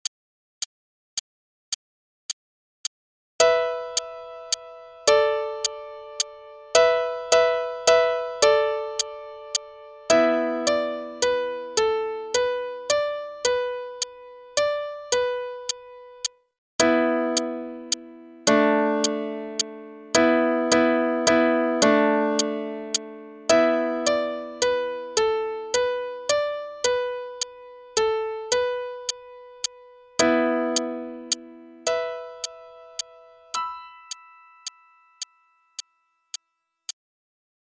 qn=108